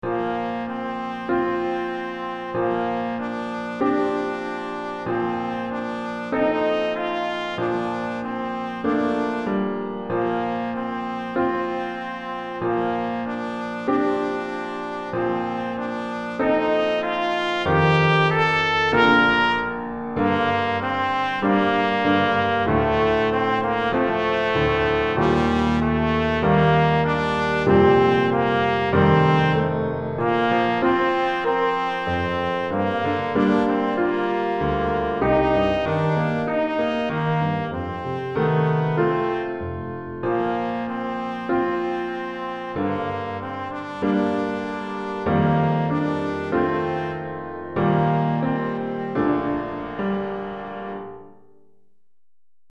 Trompette en Sib et Piano